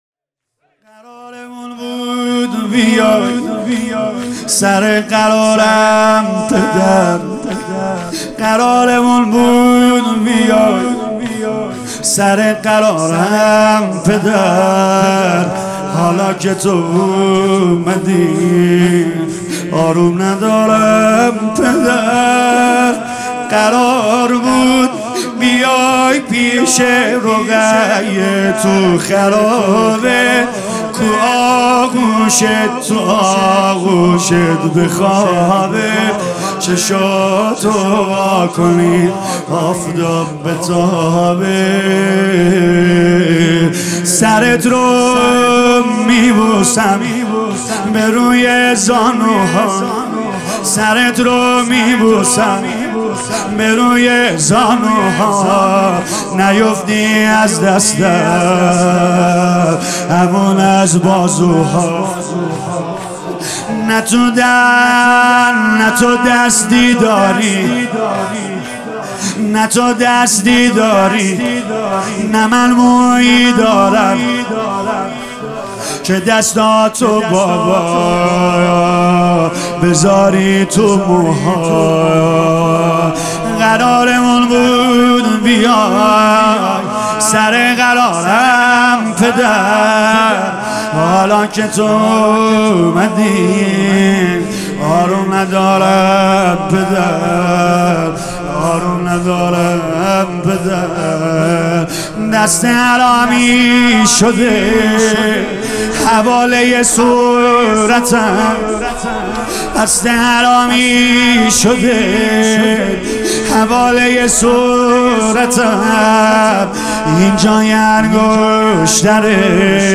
شور | قرارمون بود بیای سرِ قرارم پدر | سید رضا نریمانی